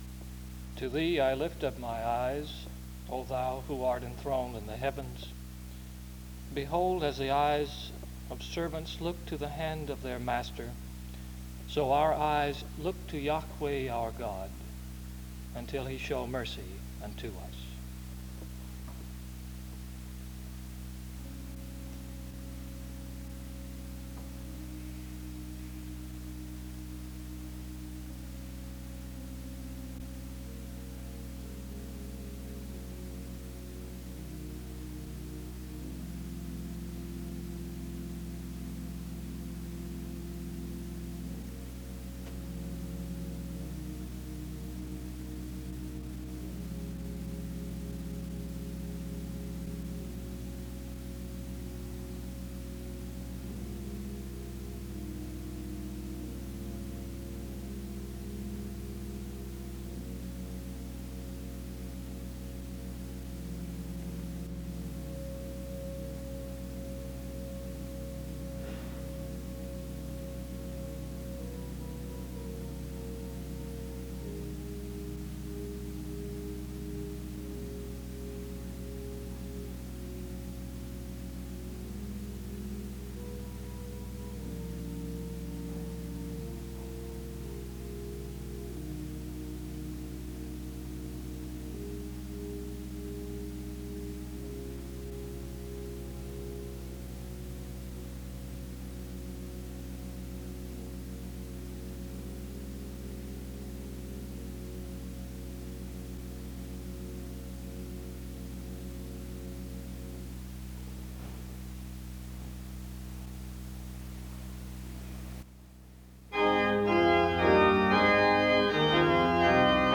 He preaches from Psalm 139. His topic is on the spiritual discipline of solitude. The service closes with music from 25:28-26:08.